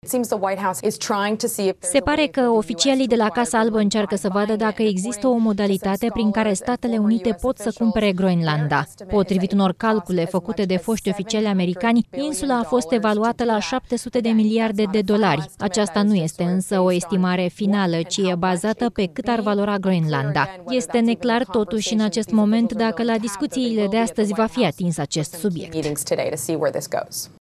14ian-18-Doamna-de-la-NBC-TRADUS-1.mp3